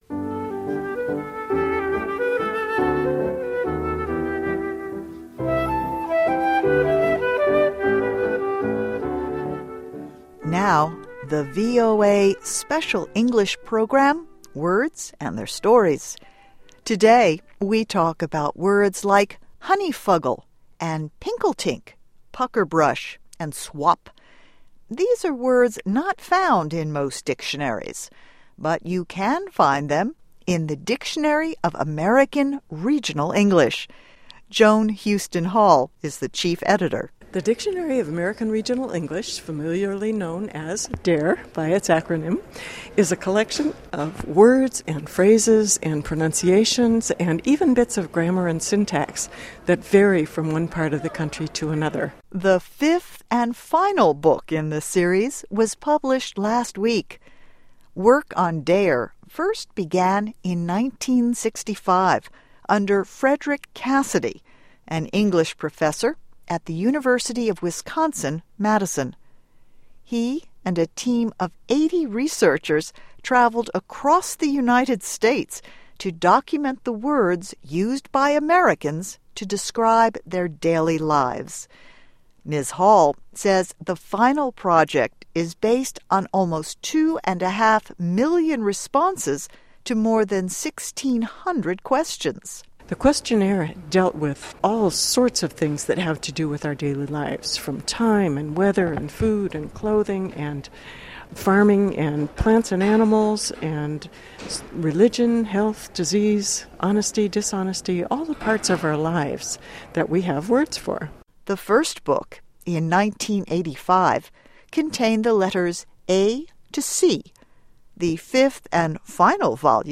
Interviewed by the Voice of America (Special English Division) about the completion of the Dictionary of American Regional English, “Words and Their Stories: A Final D.A.R.E.”